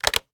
taxi_hangup.ogg